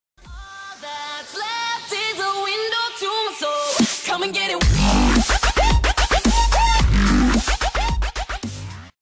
twitch-alert-sound-effect-follower-donation-subscribe-mega-1_DYunf0H.mp3